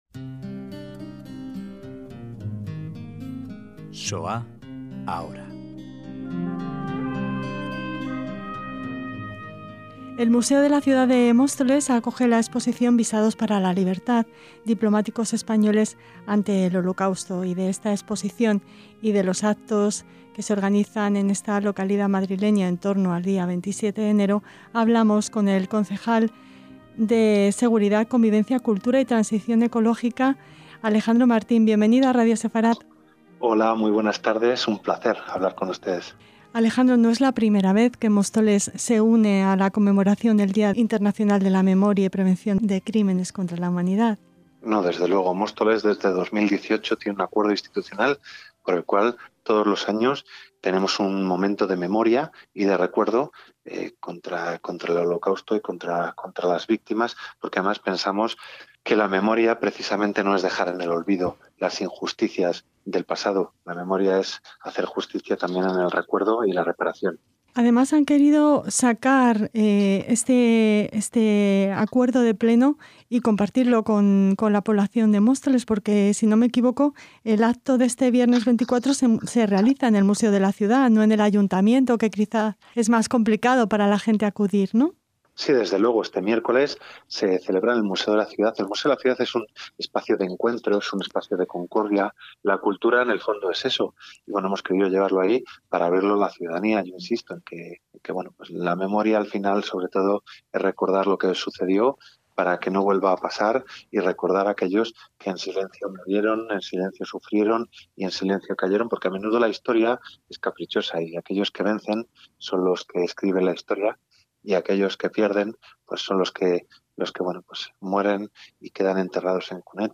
Así se expresa el Concejal de Cultura del Ayuntamiento de Móstoles Alejandro Martín al hablarnos del Acto Institucional de Homenaje a las Víctimas del Holocausto que se realizará en esta localidad madrileña el próximo viernes 24 en el Museo de la Ciudad.